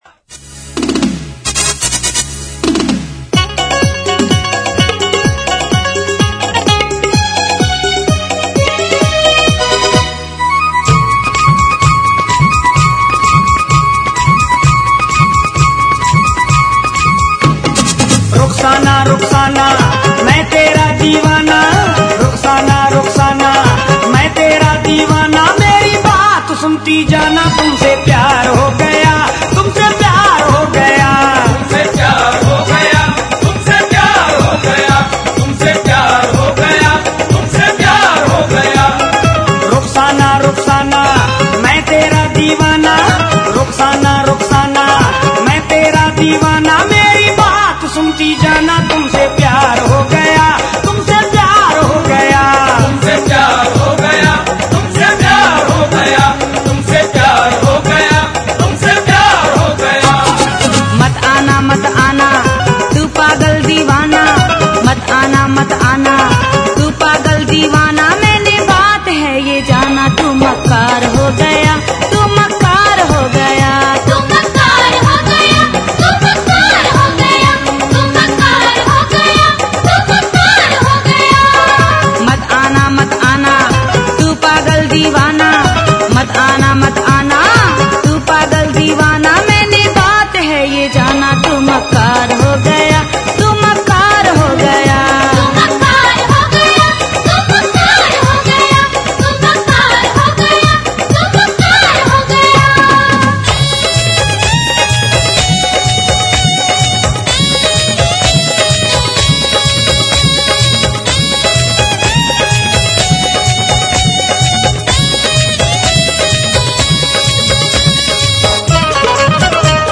Qawwalî version disco de 30 minutes!